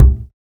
• Deep Bass Drum Sample C Key 607.wav
Royality free bass drum one shot tuned to the C note. Loudest frequency: 173Hz
deep-bass-drum-sample-c-key-607-iMv.wav